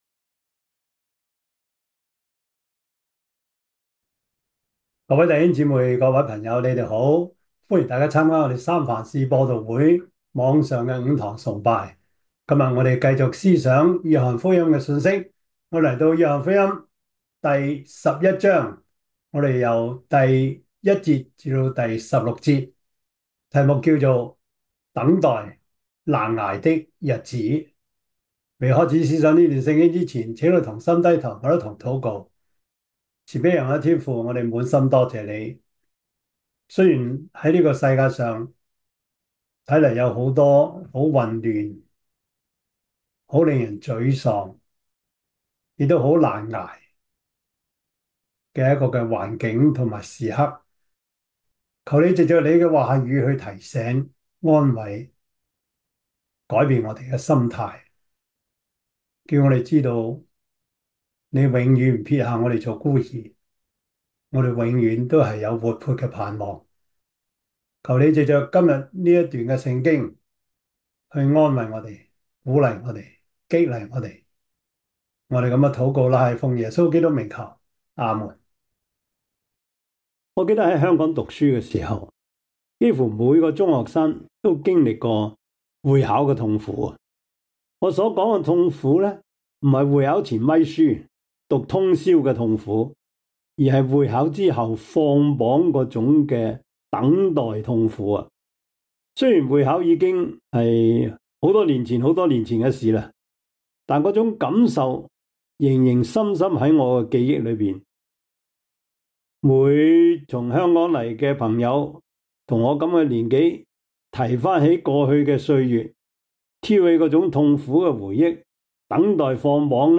約翰福音 1:1-16 Service Type: 主日崇拜 約翰福音 11:1-16 Chinese Union Version
Topics: 主日證道 « 好脂肪 Good “FAT” 第六十二課: 基督徒,教會,政治 – 第五講 – 一國兩制的案例 (下) »